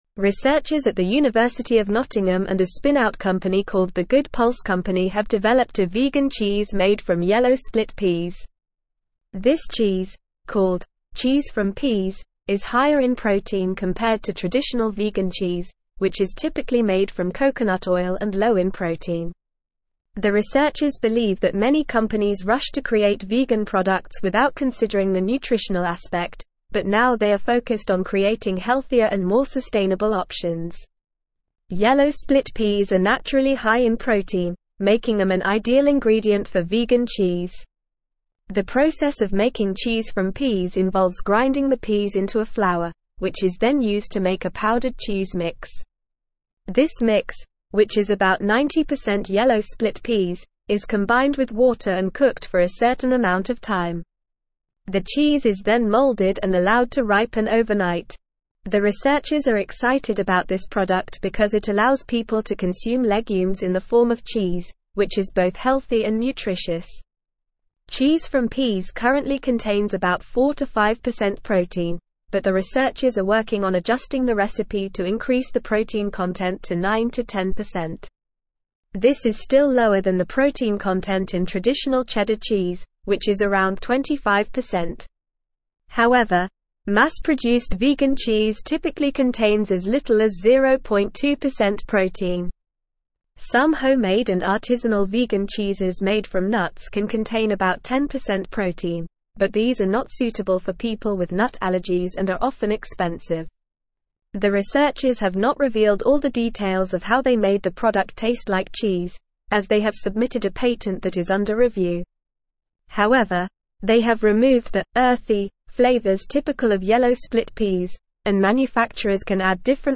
Normal
English-Newsroom-Advanced-NORMAL-Reading-Vegan-Cheese-Made-from-Peas-High-Protein-Sustainable.mp3